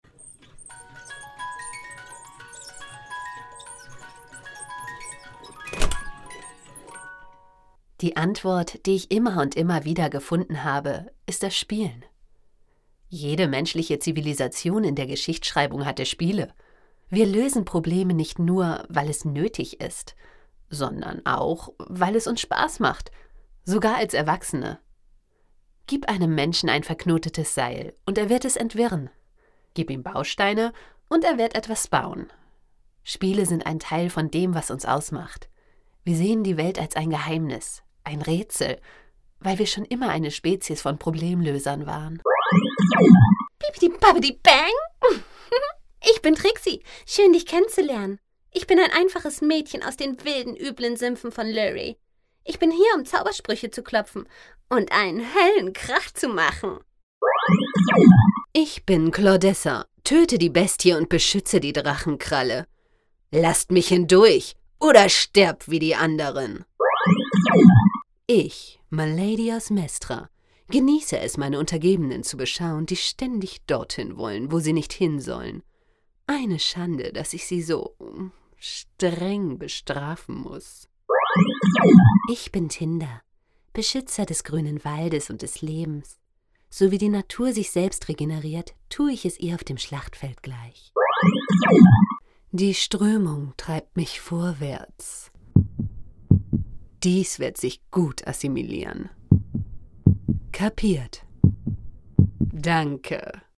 Rollenspiel: Ausschnitte aus Computerspielen Ihr Browser unterstützt kein HTML5-Audio.